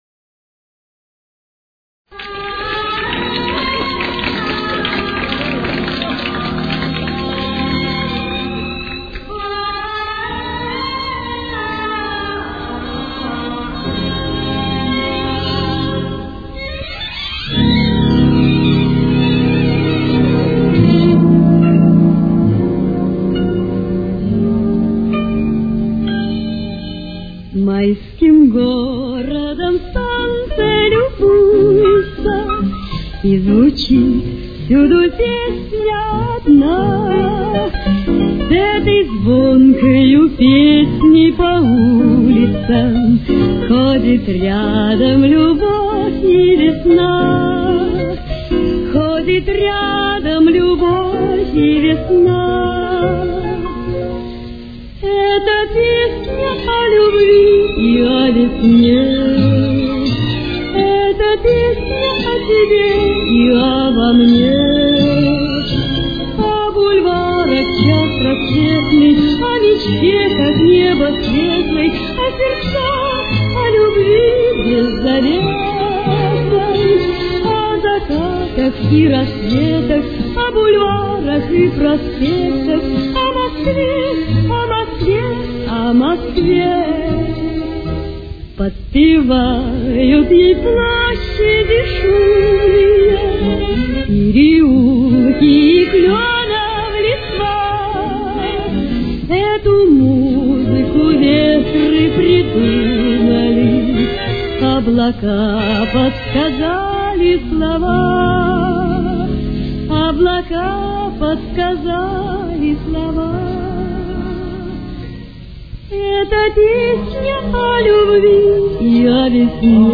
Темп: 185.